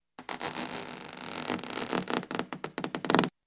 Rubber Stretch